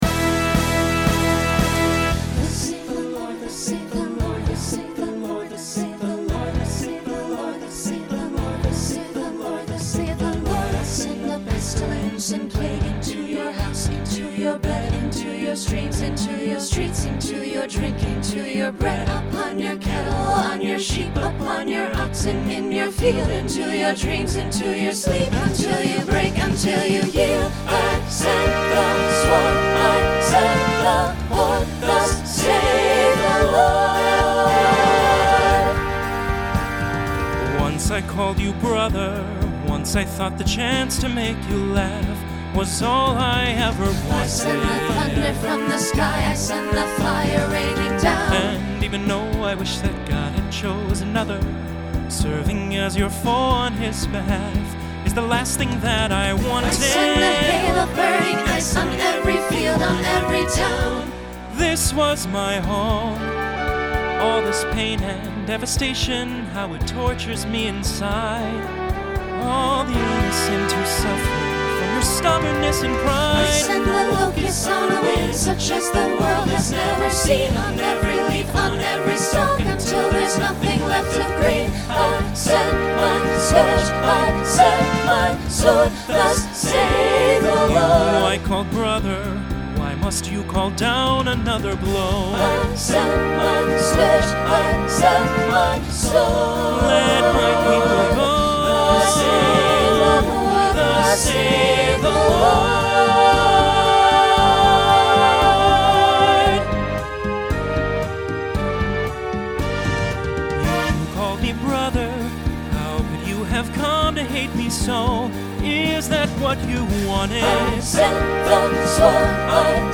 Genre Broadway/Film
Transition Voicing SATB